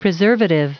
Prononciation du mot preservative en anglais (fichier audio)
Prononciation du mot : preservative